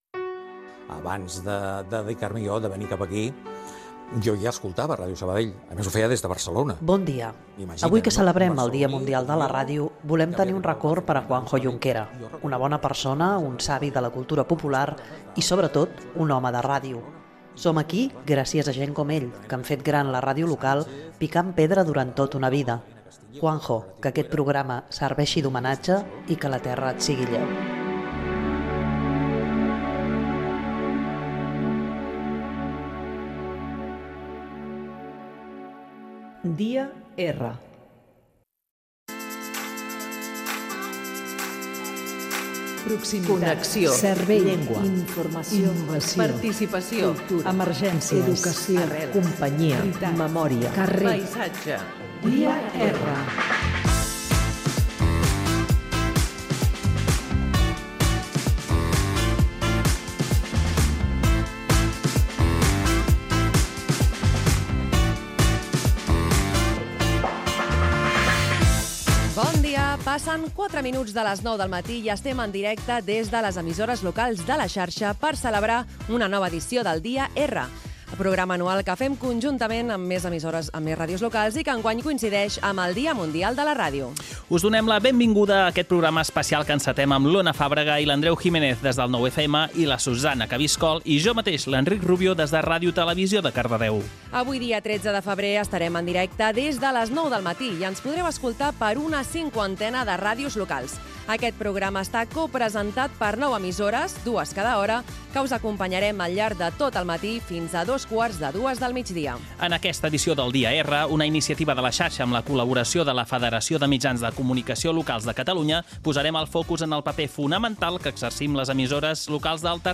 Programa especial "El dia R", amb la participació de diverses emissores de La Xarxa i de la Federació de Mitjans de Comunicació Locals de Catalunya amb motiu del dia mundial de la ràdio,
Entreteniment
Fragment extret de l'arxiu sonor de La Xarxa.